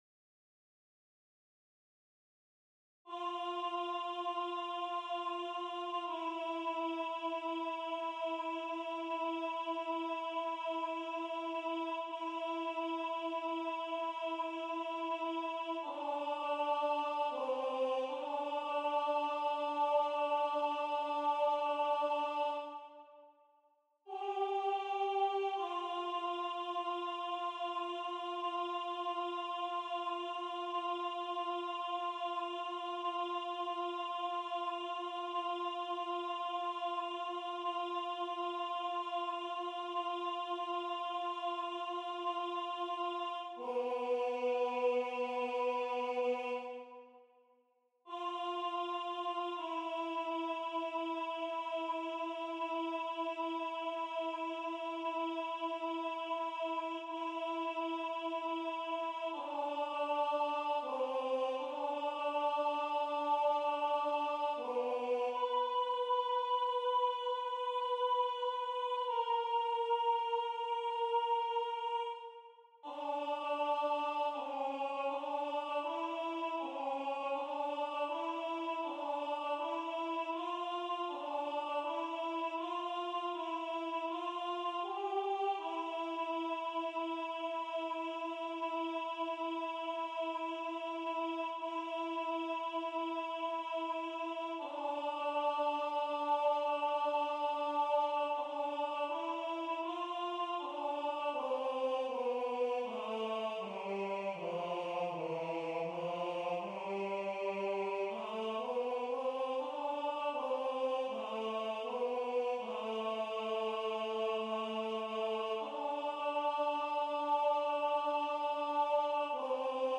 - Œuvre pour chœur à 6 voix mixtes (SAATBB) a capella
MP3 rendu voix synth.
Alto 1